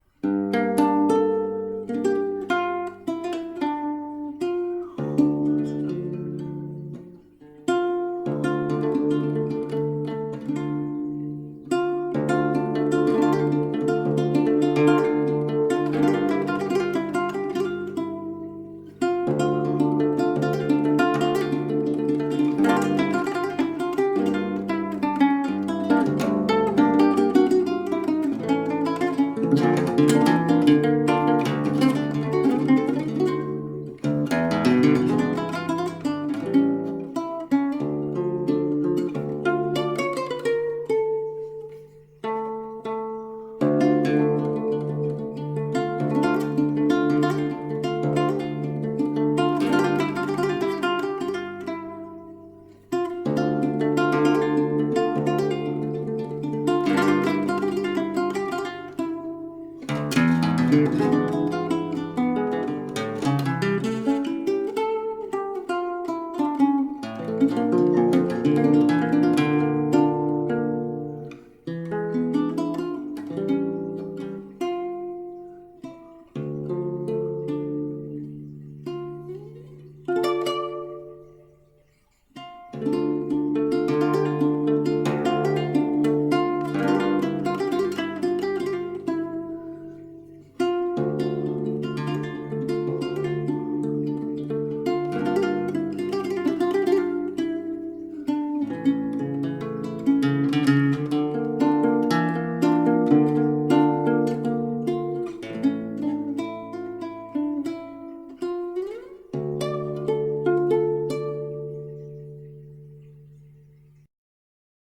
Krásná hudba* Teskná jak Fado.